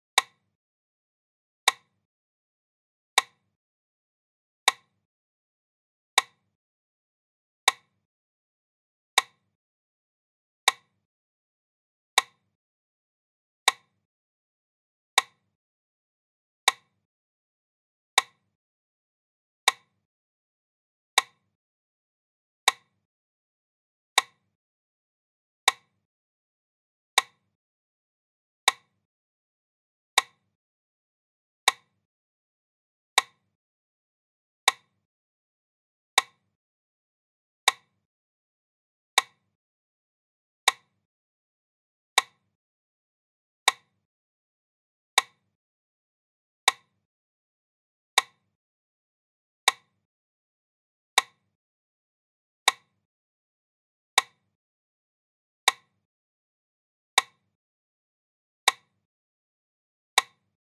Удары метронома в разных вариантах (для минуты молчания) и mp3 формате
2. 40 ударов в минуту
zvuk-metronoma-40-udarov.mp3